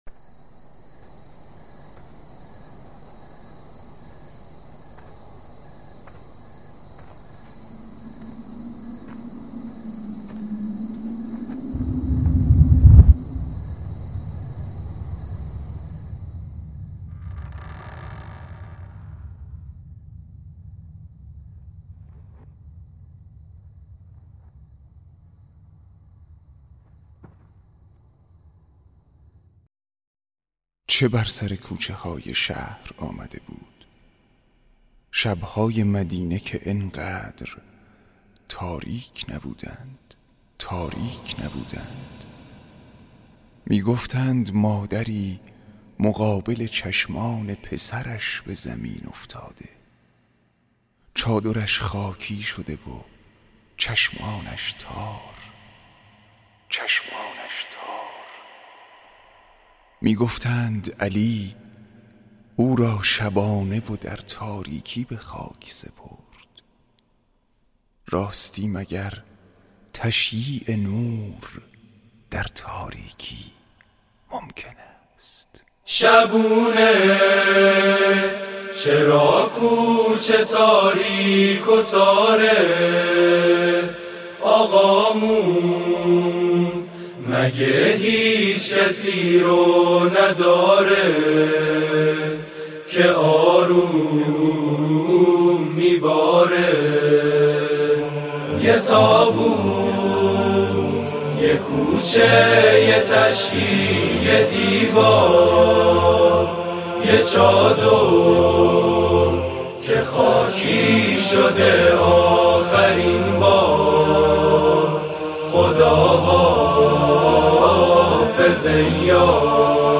نماهنگ سرود